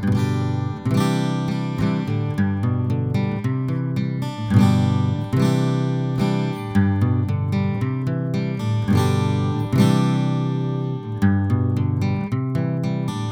Pour les prises de son, j’ai utilisé un préamplificateur Neve 4081 quatre canaux avec la carte optionnelle Digital l/O qui convertit l’analogique en numérique AES sur une SubD25 ou en Firewire.
Les échantillons n’ont subi aucun traitement.
Enregistrements d’une guitare acoustique (AIFF) :
NeumannU89-Prise2-.aif